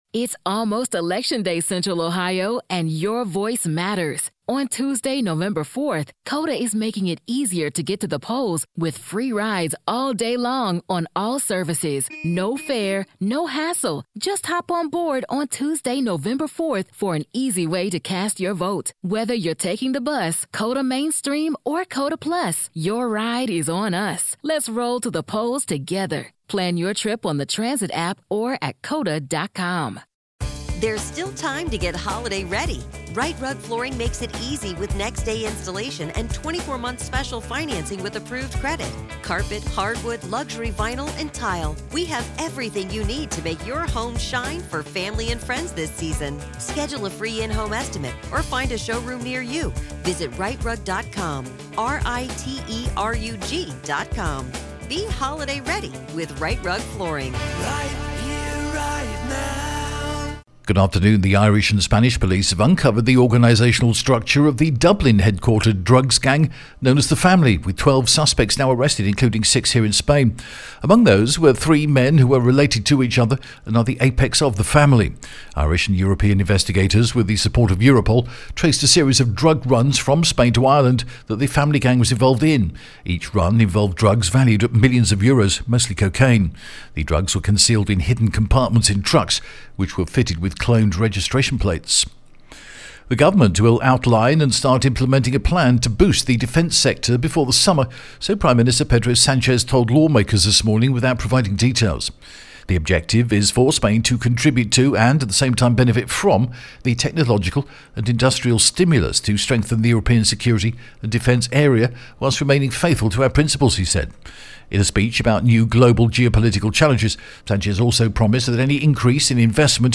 The latest Spanish news headlines in English: March 26th 2025